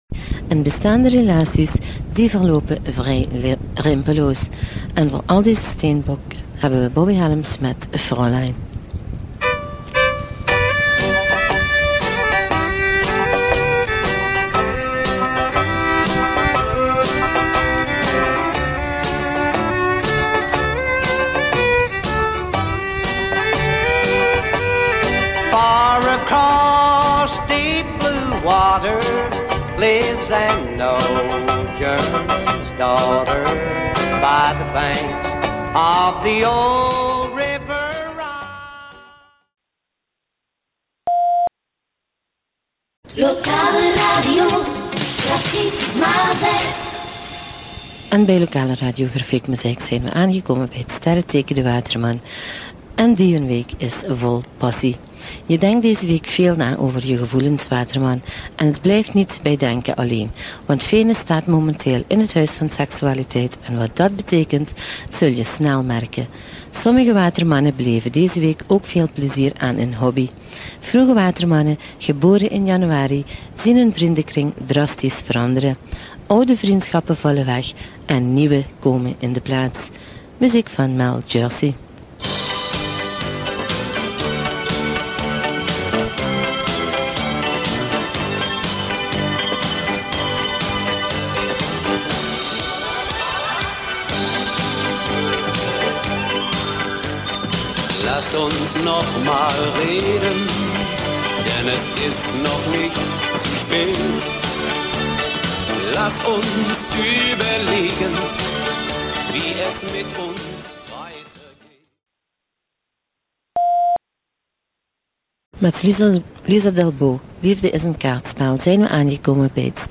Op een specifieke doordeweekse ochtend was er ook plaats voor de wekelijkse horoscoop.